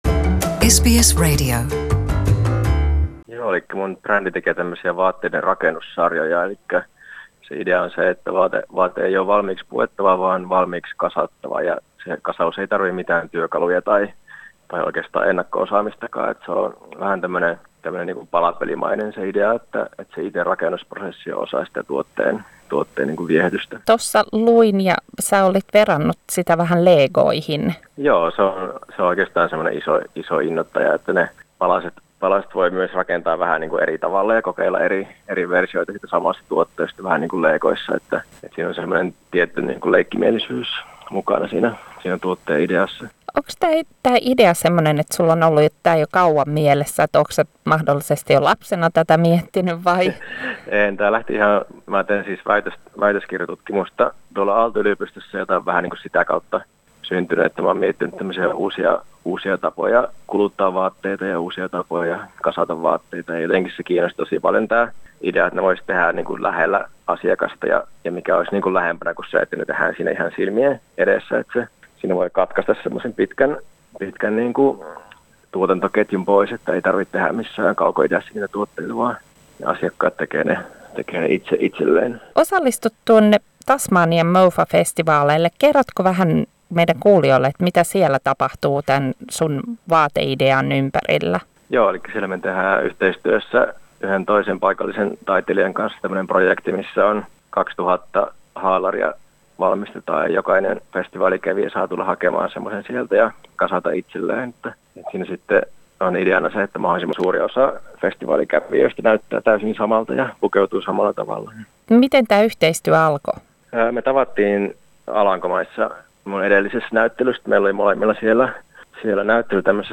Haastateltavana